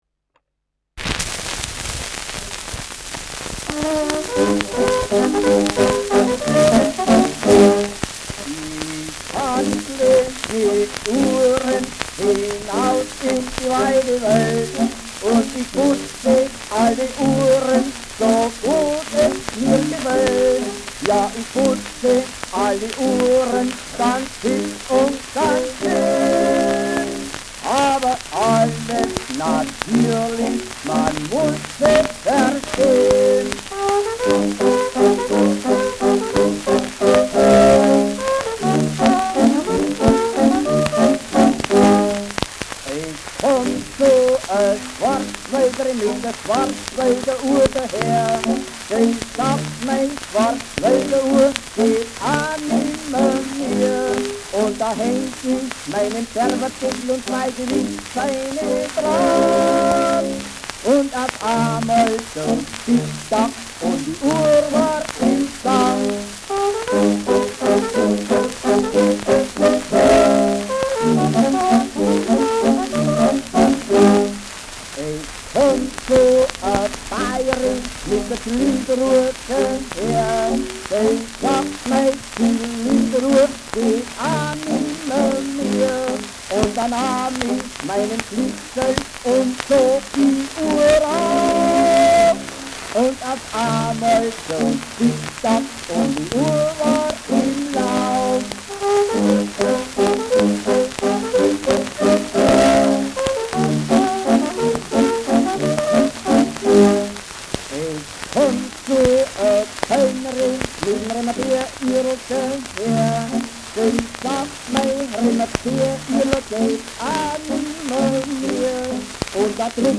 Der Schwarzwälder Uhrmacher : mit Gesang
Schellackplatte
präsentes Rauschen : starkes Knistern : stark abgespielt : leiert